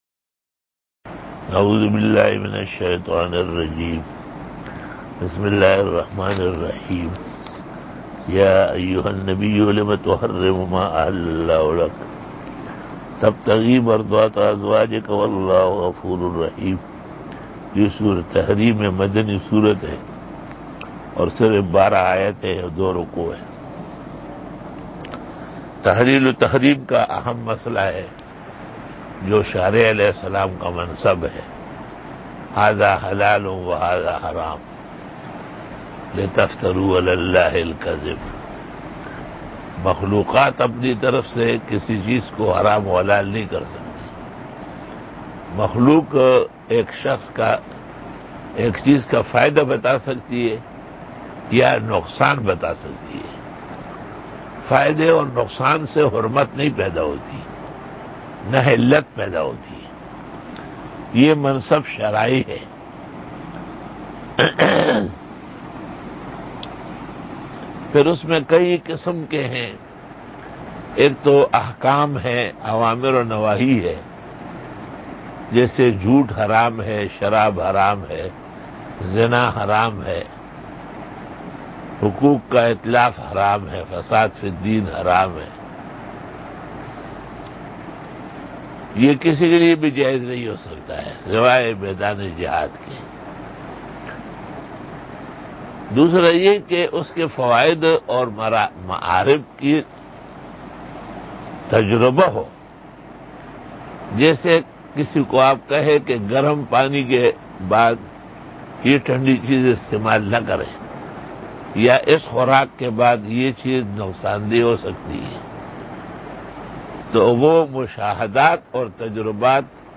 82 Quran Tafseer 08 July 2020 ( 16 Zil Qaadah 1441 H) - Wednesday Day 82